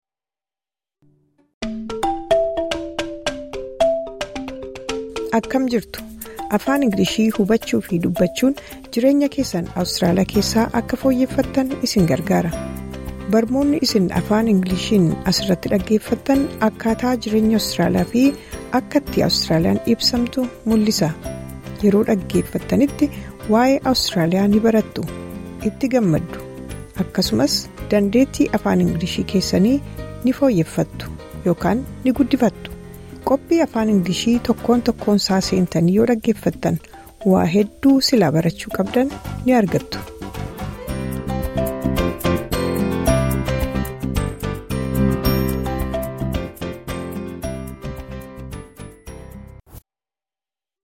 This lesson is suitable for intermediate-level learners.